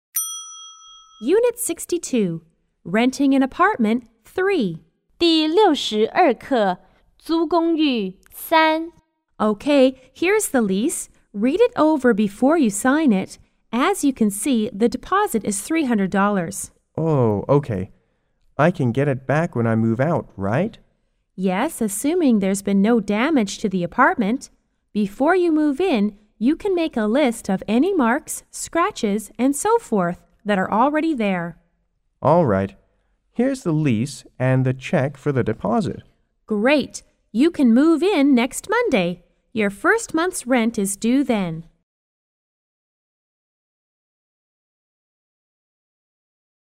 M= Manager R= Renter